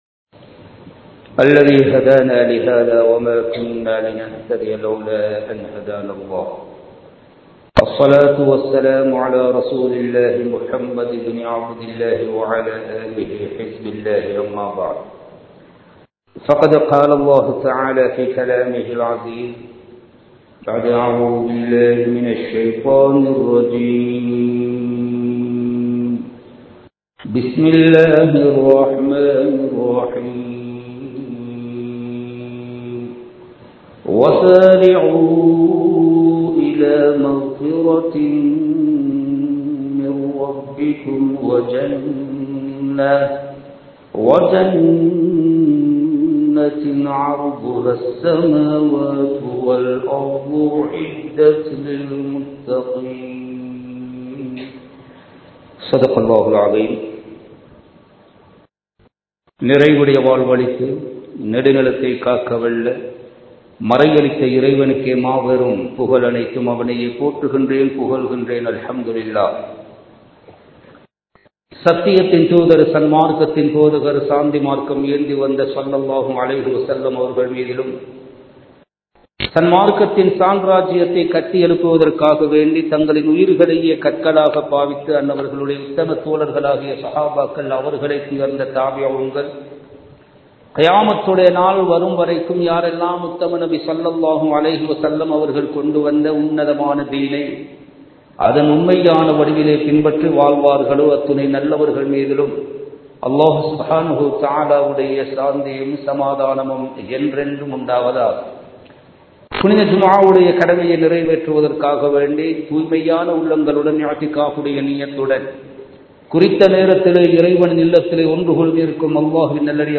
ஷஃபான் மாதத்தின் சிறப்புகள் | Audio Bayans | All Ceylon Muslim Youth Community | Addalaichenai